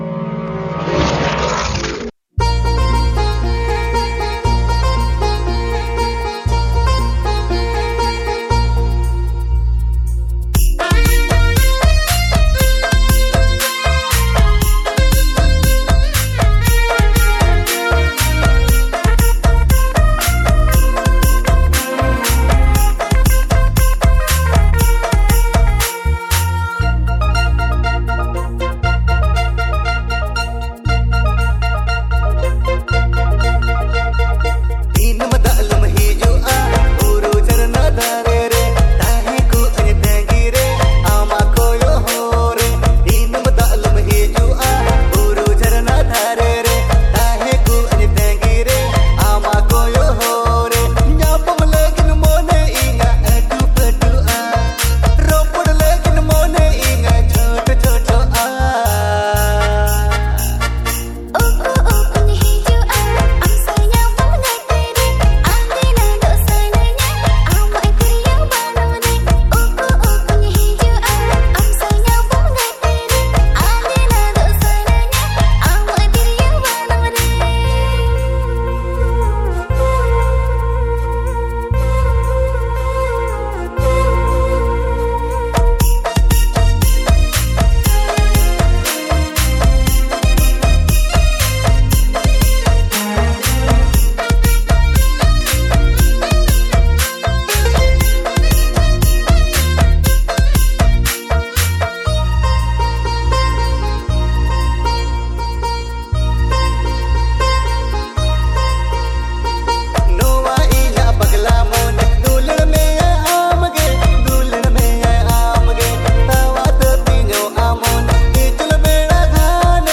• Male Artist
• Female Artist